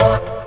54_03_organ-A.wav